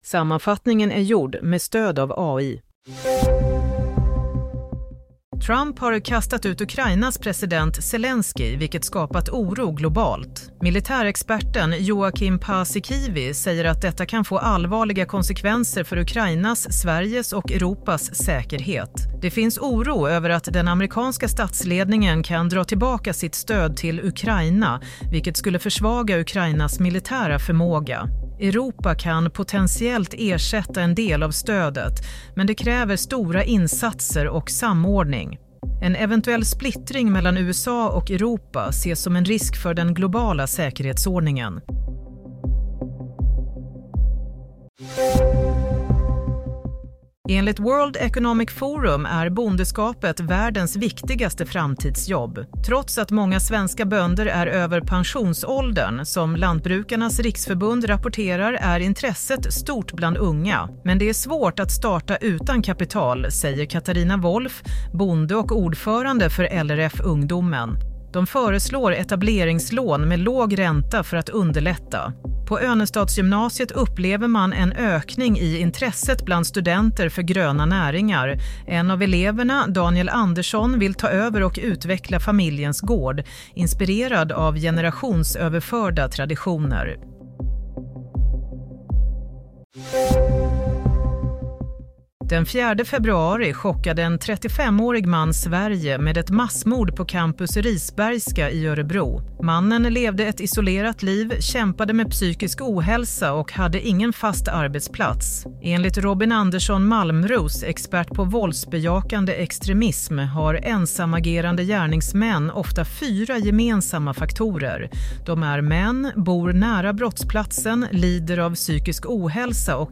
Nyhetssammanfattning - 1 mars 15:30